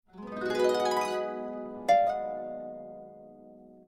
Samsung Galaxy Bildirim Sesleri - Dijital Eşik
Harp Glissando
harp-glissando.mp3